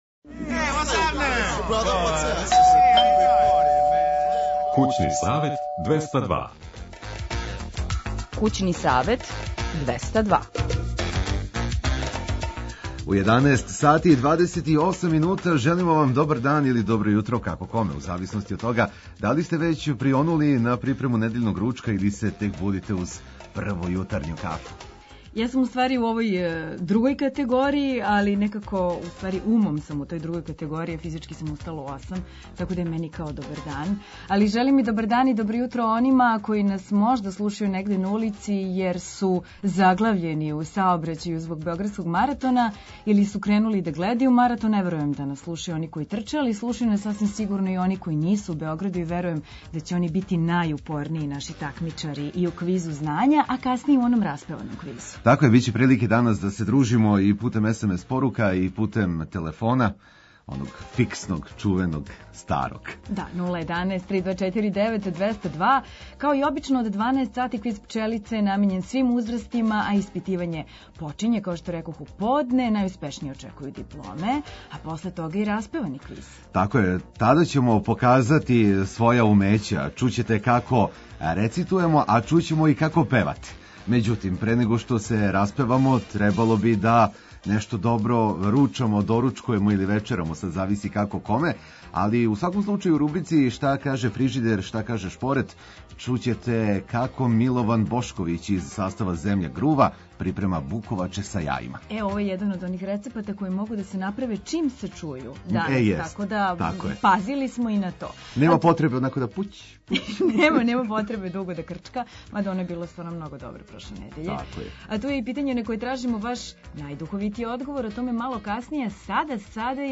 Пре него што почне „Распевани квиз”, у ком ћемо показати како рецитујемо и где ћемо чути како певате, даћемо предлог јеловника за наредне дане.